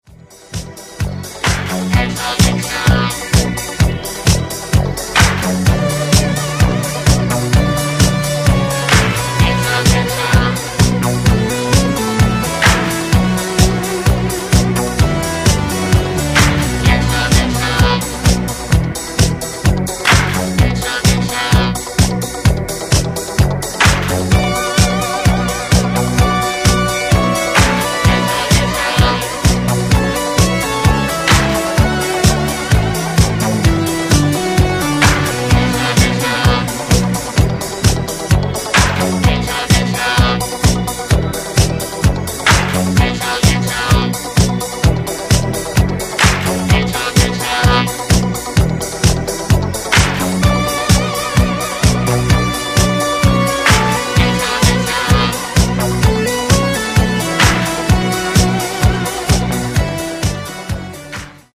italian disco group
timeless italo disco
the darker side of electronic disco